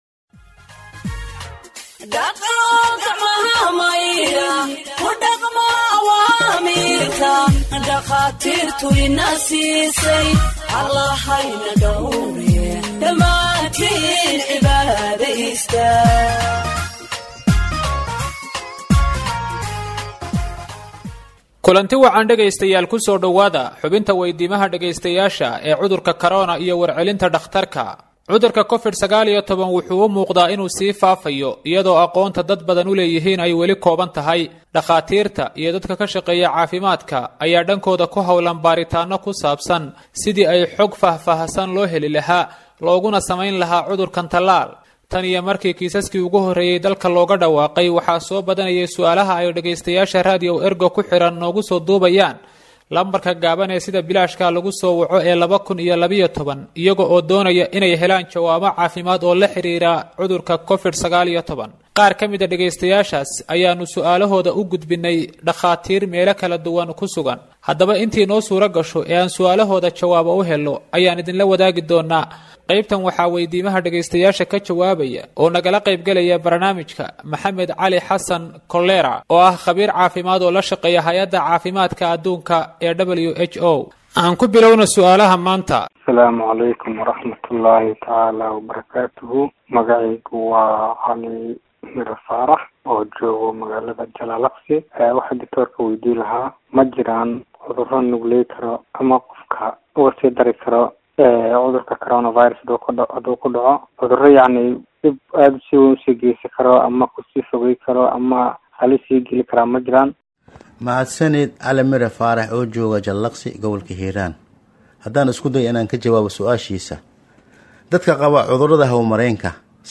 Health expert answers listeners’ questions on COVID 19 (23)